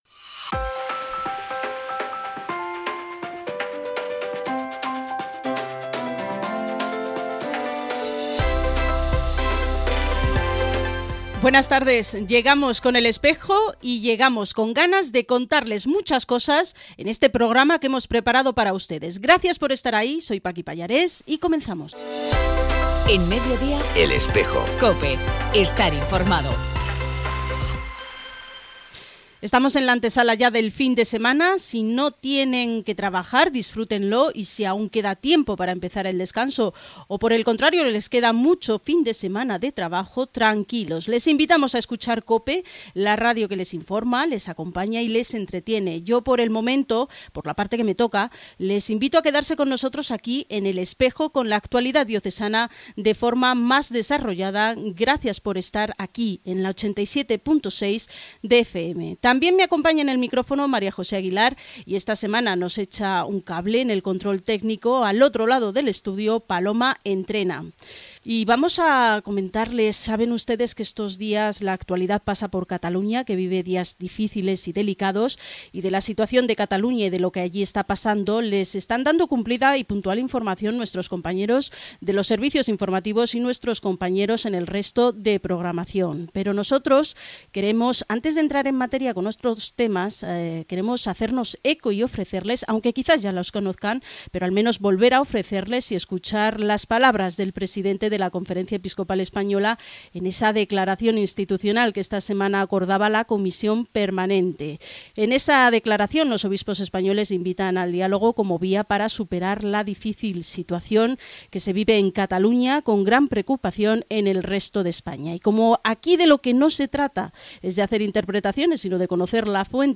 Emisión del programa “El Espejo” en Cope Granada (87.6 FM) hoy viernes, 29 de septiembre.